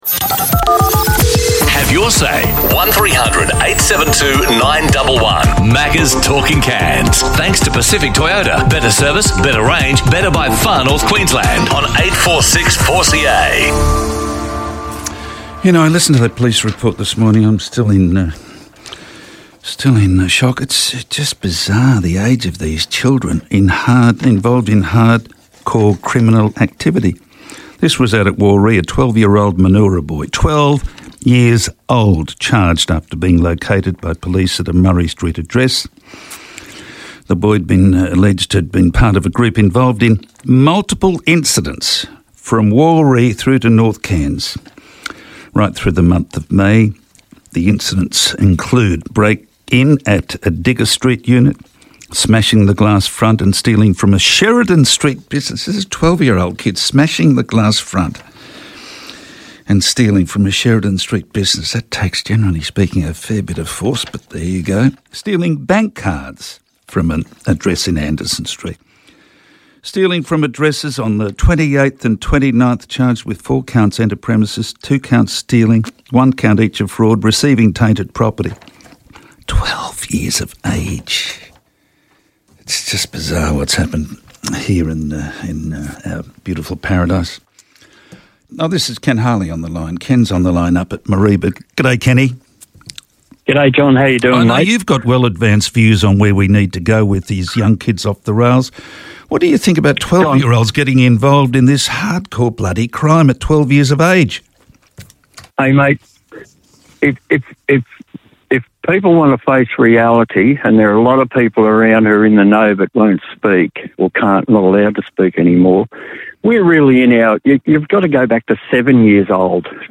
a talkback caller from Mareeba